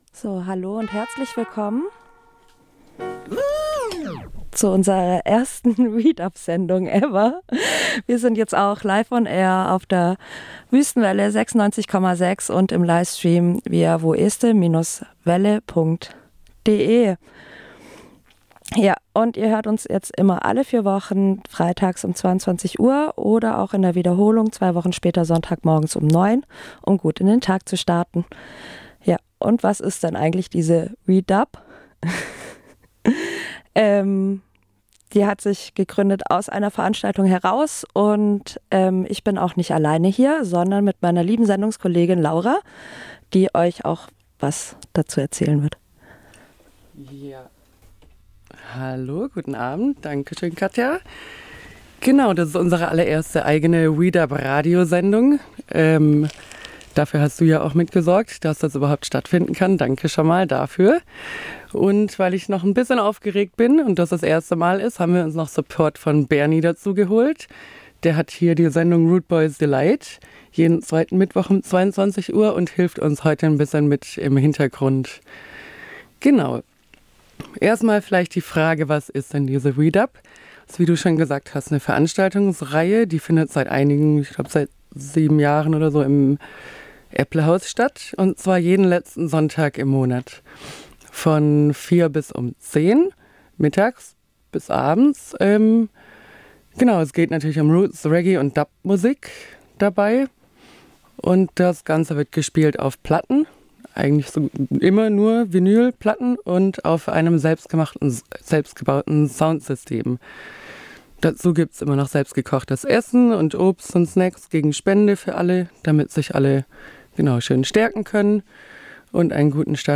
Die Redaktion WE DUB war zum ersten mal auf Sendung LIVE auf der Wüsten Welle 96,6fm am Freitag, 22.11.2024 zu hören! Dazu hier ein kurzer Zusammenschnitt zum nachhören als Beitrag für alle WE DUB lovers. Ebenso erzählt die WE DUB REDAKTION im Hörbeitrag mehr über das Lineup am Wochenende im Epplehaus.